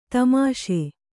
♪ tamāṣe